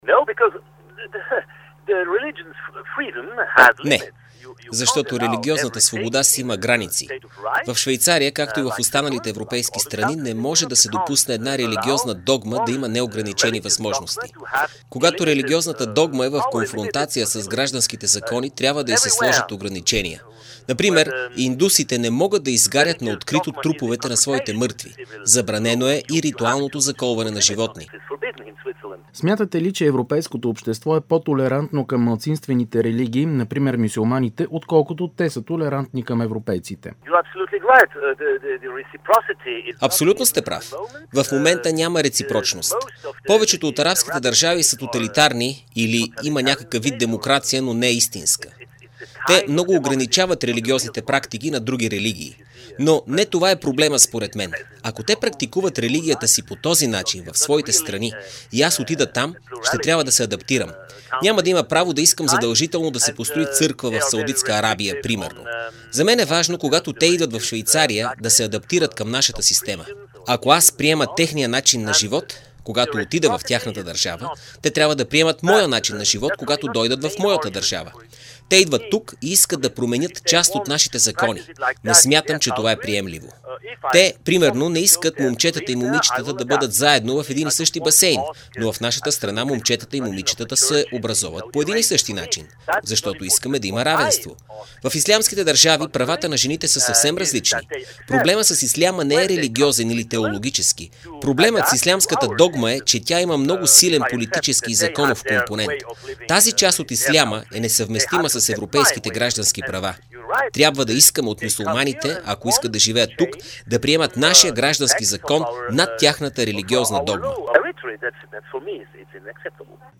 Интервю с Оскар Фрайзингер - 1 част